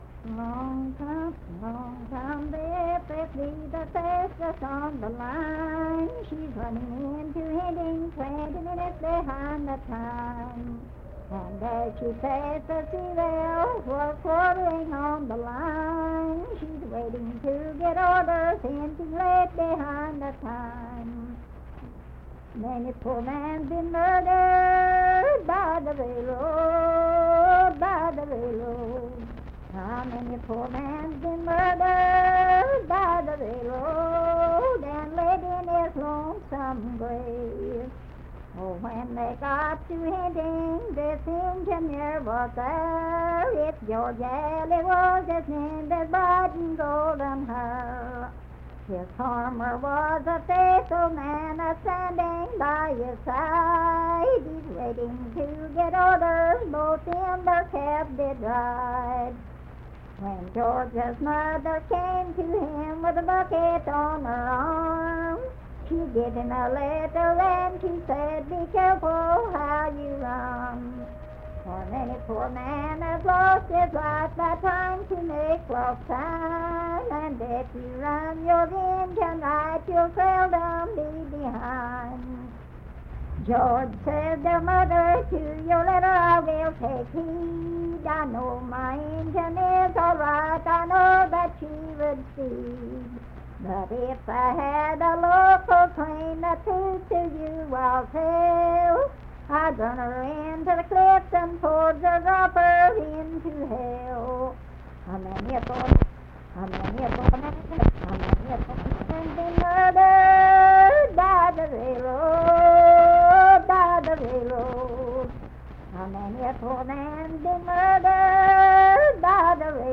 Unaccompanied vocal music performance
Verse-refrain 9d(4) & R(4).
Voice (sung)